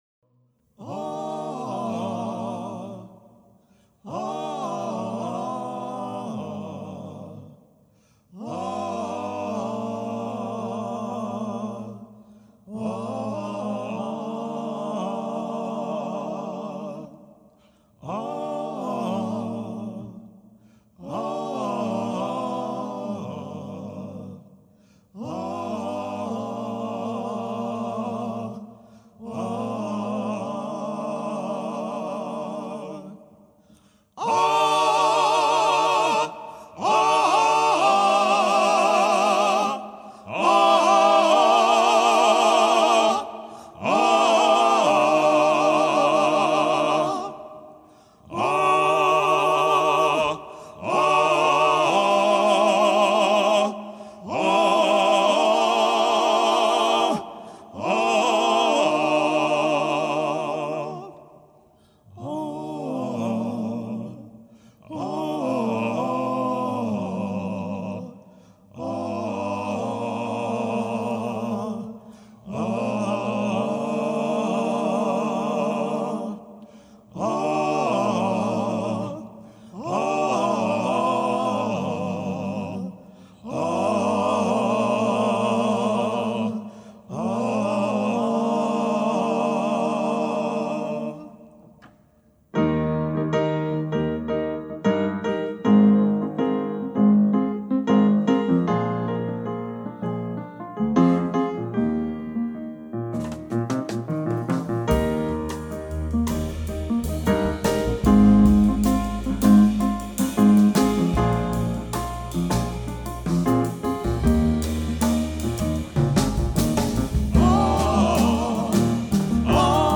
piano, vocals
electric & accoustic bass
percussion